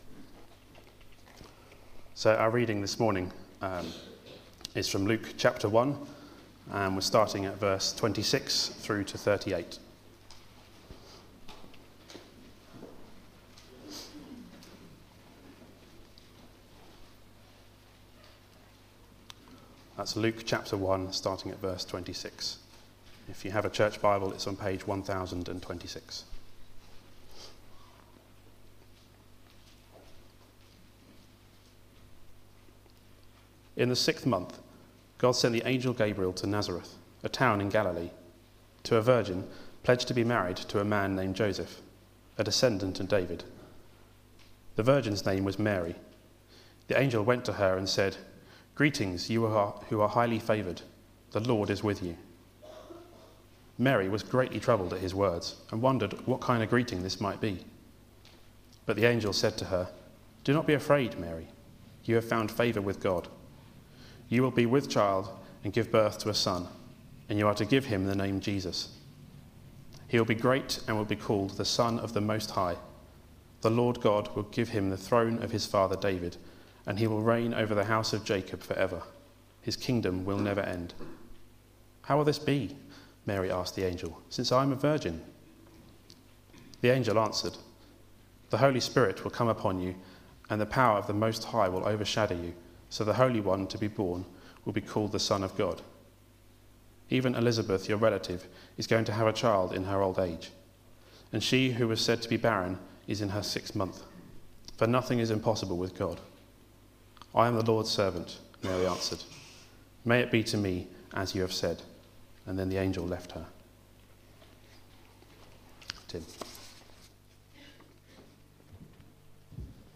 Media for Sunday Service on Sun 12th Oct 2014 11:00
Sermon